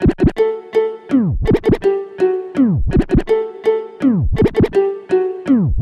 暗钟环路
描述：钟形圈套
标签： 165 bpm Trap Loops Bells Loops 1 002.45 KB wav Key : A
声道立体声